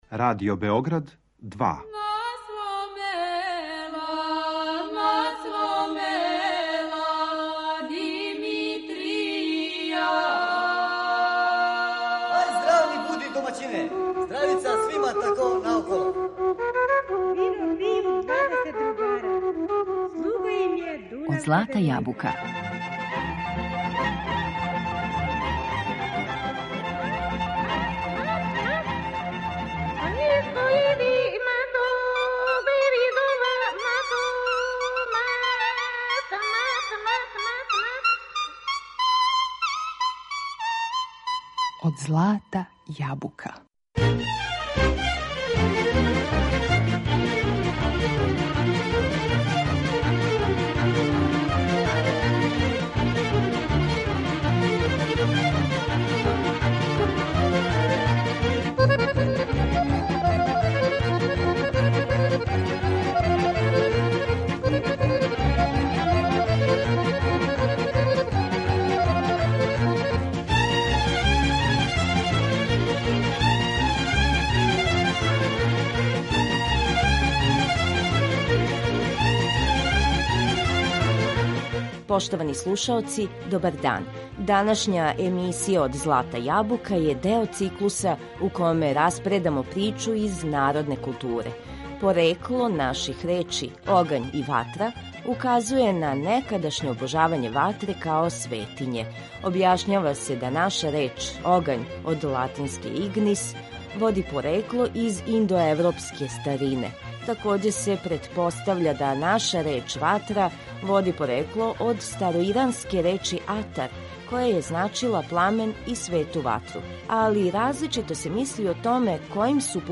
Говорићемо о огњишту и веригама, значају који су представљали кроз векове, а споменућемо и анегдоте забележене у етнографској литератури. Најлепша кола у извођењу наших народних ансамбала илустроваће причу о предањима и веровањима нашег народа.